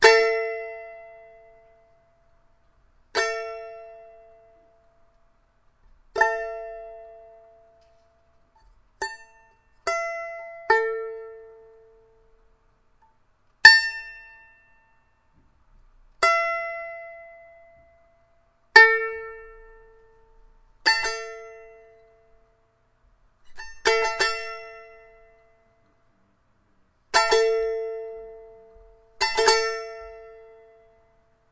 弦乐棒 " 弦乐棒8音阶条琴
描述：Strummed plucked用Blue Snowball话筒录制，16bit
Tag: 俯仰 乱弹 strumstick 乐器 吉他 strumstick 拔毛 笔记 样品